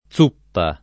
+Z2ppa] s. f. — ant. suppa [S2ppa]: Che vendetta di Dio non teme suppe [k% vvend%tta di dd&o non t%me S2ppe] (Dante), qui con un sign. discusso (proprio «zuppa» per allus. a un uso superstizioso? o una voce omonima per «corazza»?) — suppa la forma originaria, attestata già nella traduzione lat. (sec. V o VI) degli scritti di medicina di Oribasio, poi presente nelle prose volgari di trecentisti tra i maggiori, ma presto sopraffatta dalla forma con z- senza lasciare altro che scarsi residui in autori settentr. fino al ’700; e tutti con z- i der. inzuppare (da cui l’agg. zuppo), zuppiera, ecc. — pn. sorda dell’s- tanto nella forma it., dov’è ovvia, quanto nell’etimo germanico; e pn. sorda della z- che le è subentrata, concordem. attestata nei dizionari e tuttora domin. in tutta la Tosc. e in parte dell’It. mediana — sim. i cogn. Suppa, Zuppa